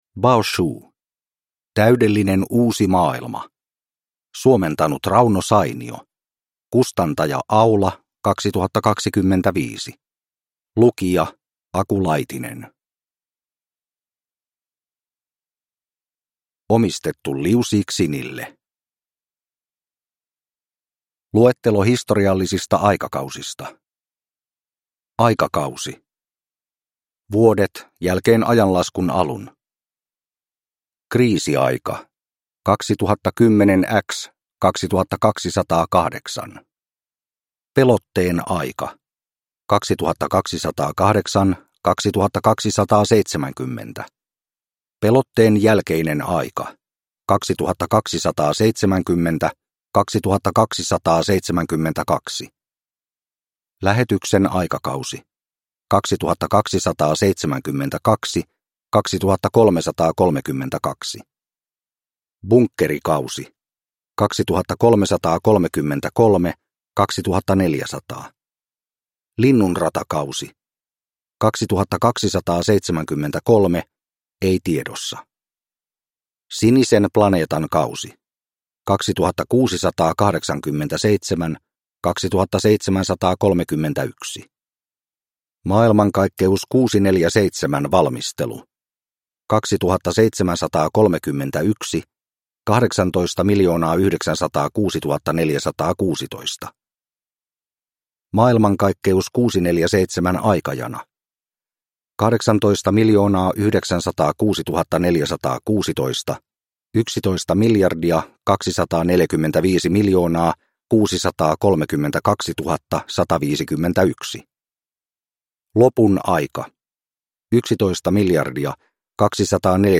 Täydellinen uusi maailma (ljudbok) av Baoshu ,